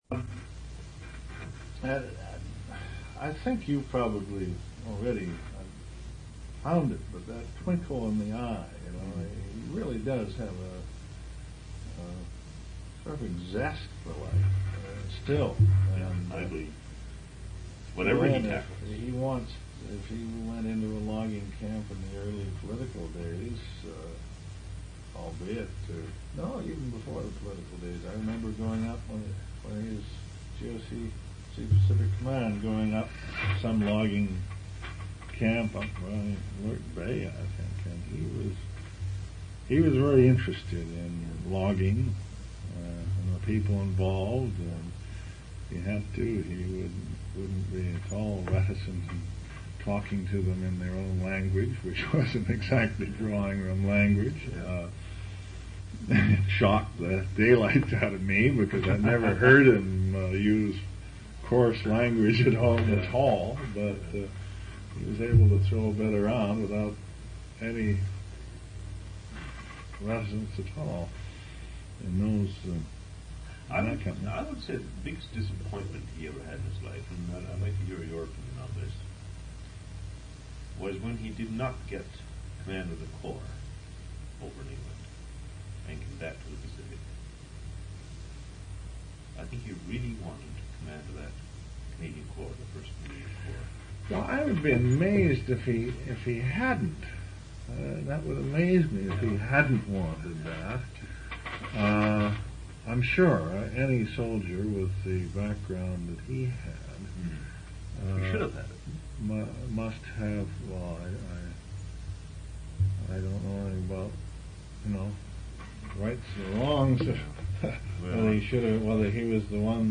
Two original sound tape reels (ca. 120 min.) : 1 7/8 ips, 2 track, mono.